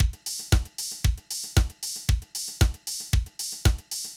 MOO Beat - Mix 2.wav